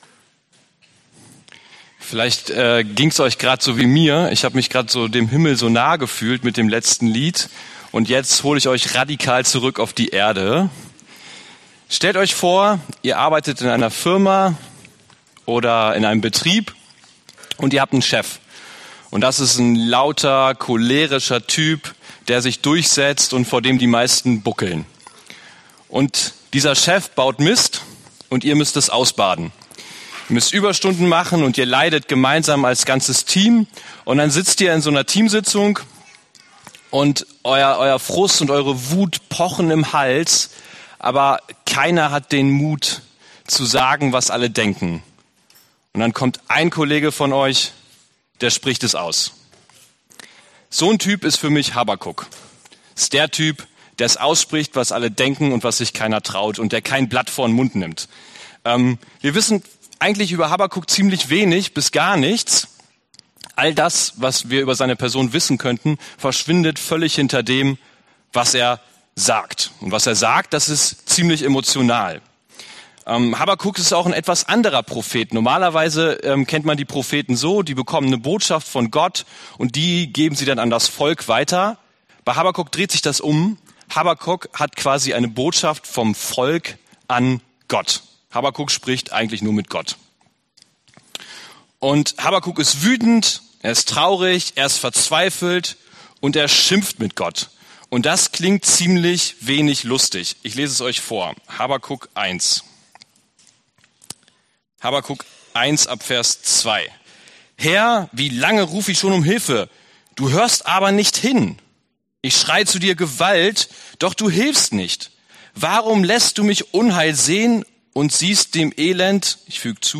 Habakuk Passage: Habakuk 1-2,5 Dienstart: Predigt « Aber siehst du auch anden anderen?